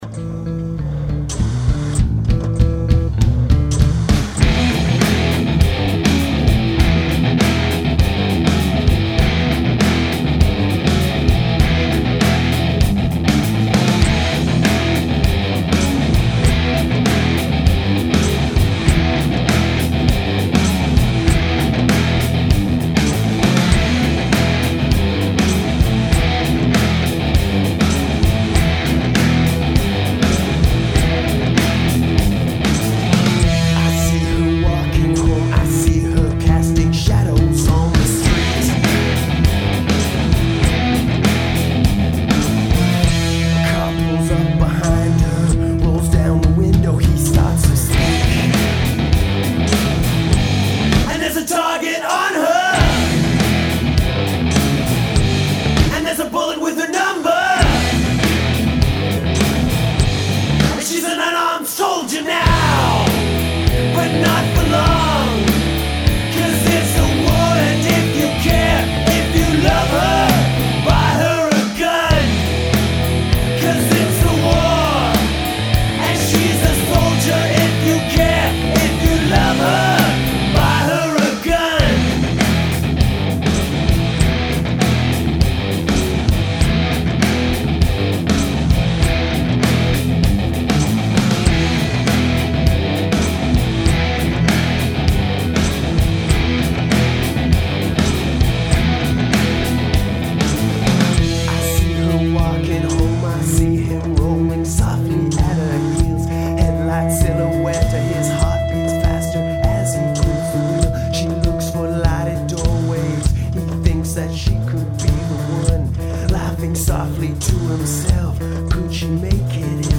90’s alt-rockers